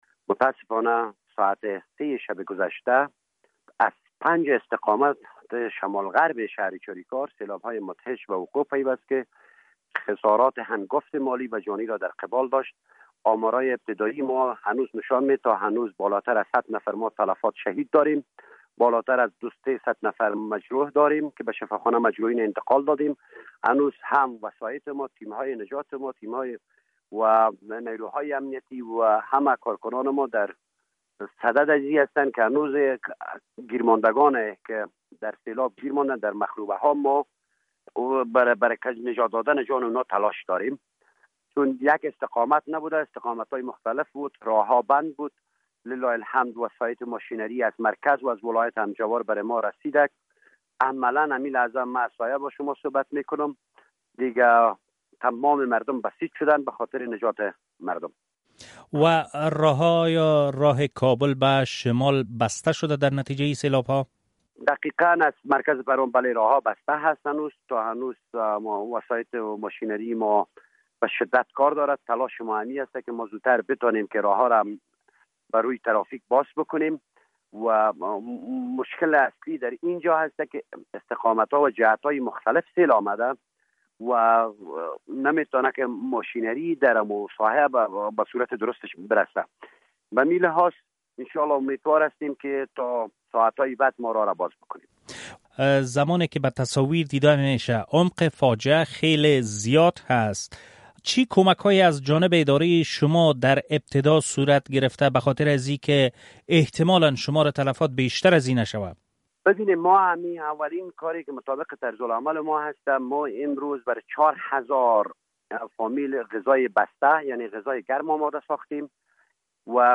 مصاحبه - صدا
غلام بهاءالدین جیلانی امروز چهارشنبه به رادیو آزادی گفت، احتمال می‌رود شمار جان باخته‌گان بیشتر از این شود.